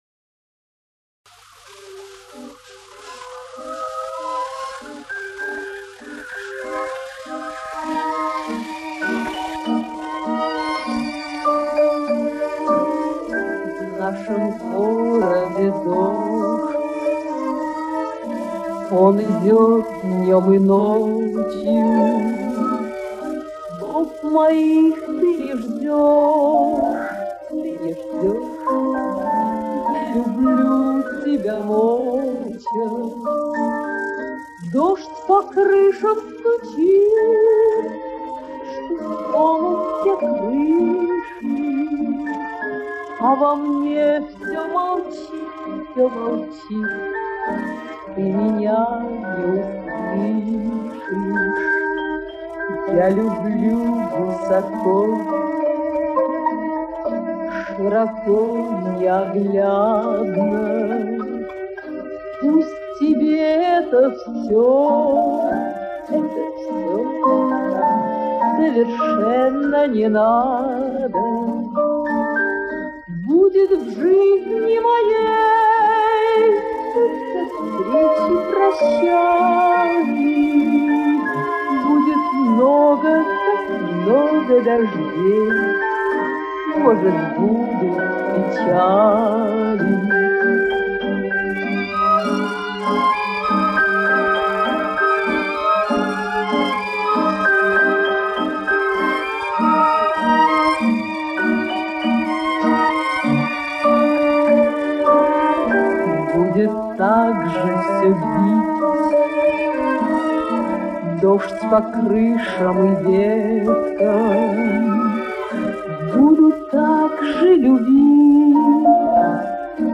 Вот, послушай, я убрал, насколько можно было, шум.